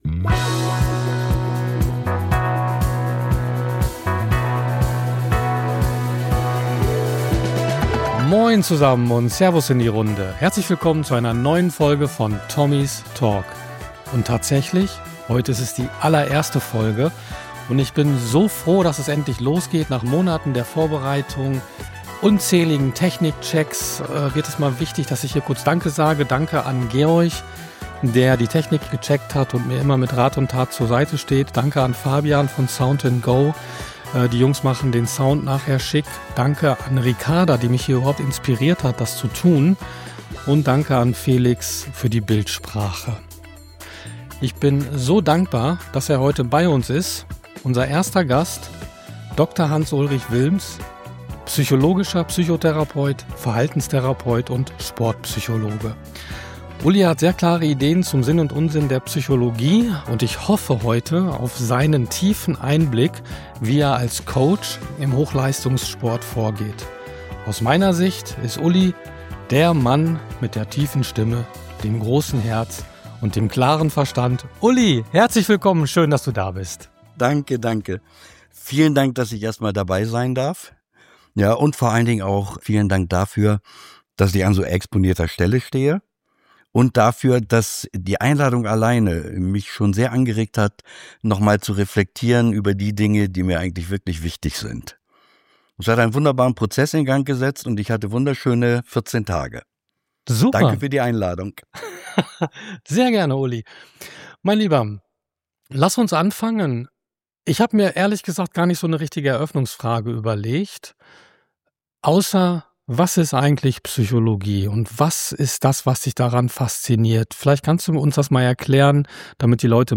Der Mann mit der tiefen Stimme, dem großen Herz und dem klaren Verstand.